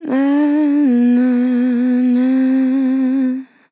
Categories: Vocals Tags: dry, EHH, english, female, fill, LOFI VIBES, nhh, sample